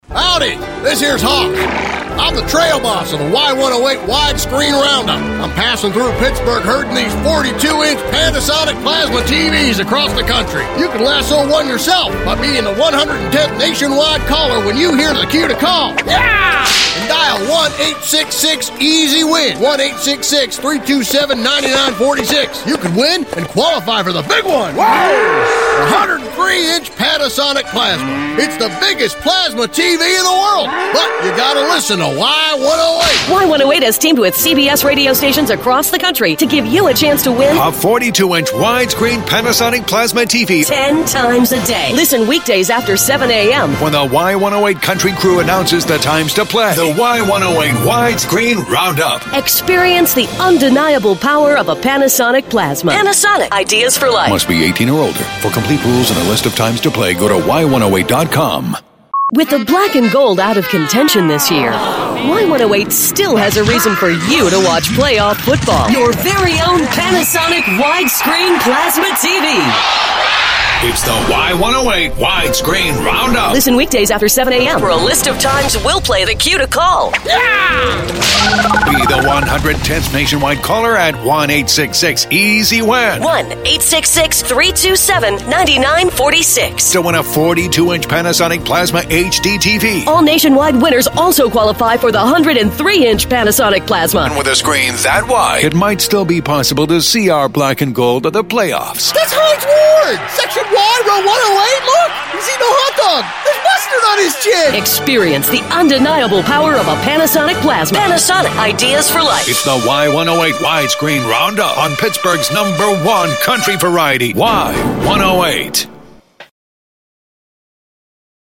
Widescreen Roundup Promos (2)